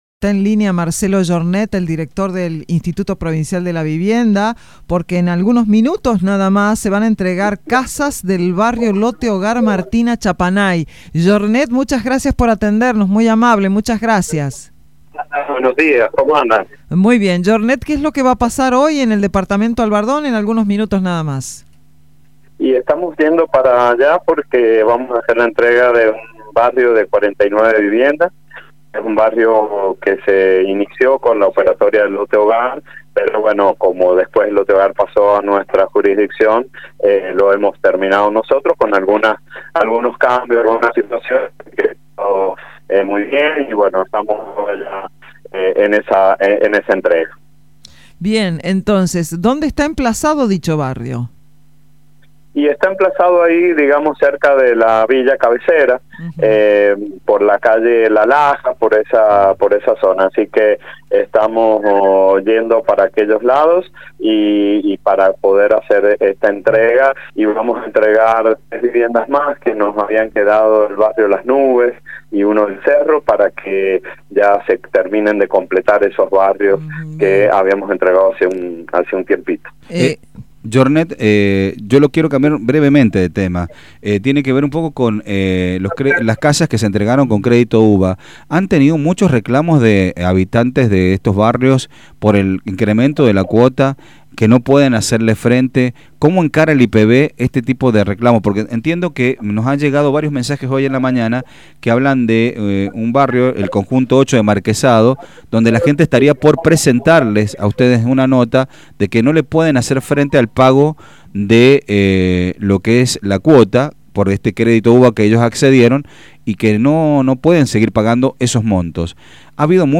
En la mañana de este miércoles, Marcelo Yornet, director del Instituto Provincial de la Vivienda (IPV) estuvo en los micrófonos de Radio Sarmiento para hablar acerca de la entrega de viviendas en el departamento Albardón.